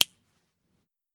El programa també fa servir dos fitxers de so, un per al dispar,
pew.wav